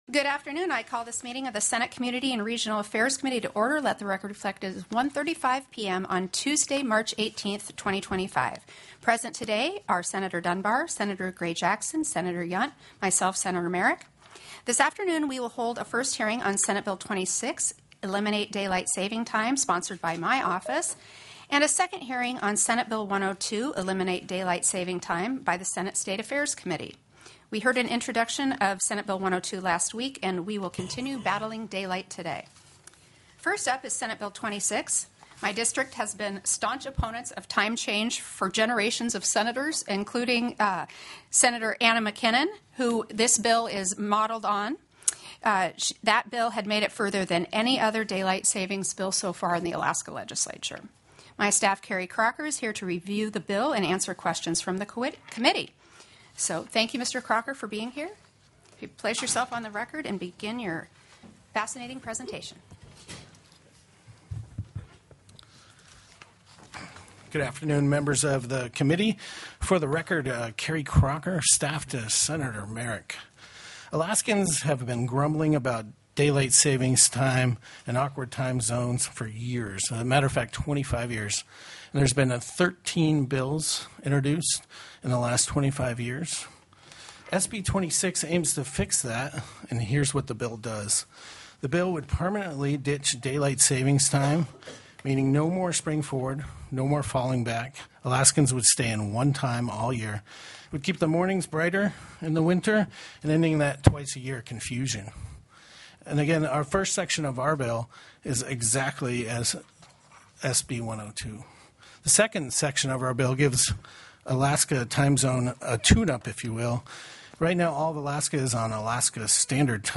03/18/2025 01:30 PM Senate COMMUNITY & REGIONAL AFFAIRS
The audio recordings are captured by our records offices as the official record of the meeting and will have more accurate timestamps.